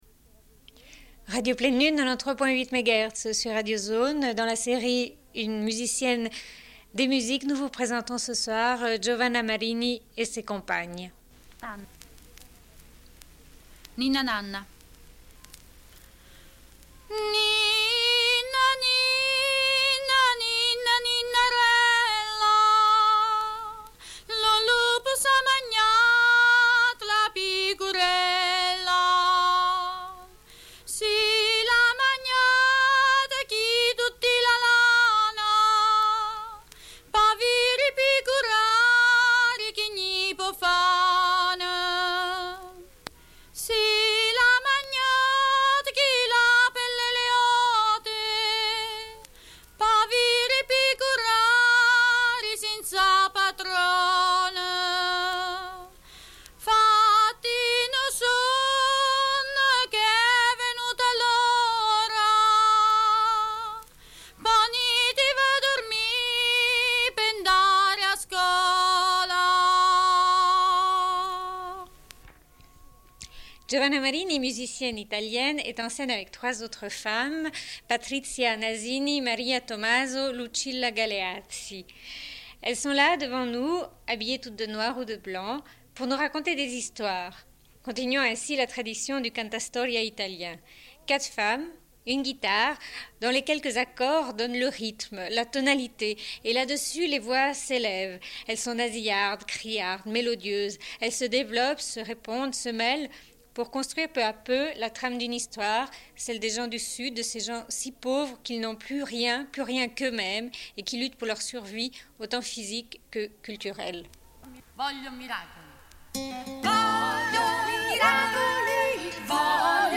Une cassette audio, face A30:59
Émission dédiée à Giovanna Marini. Diffusion d'un entretien mené avec elle à l'occasion d'un concert à Genève puis écoute de musique.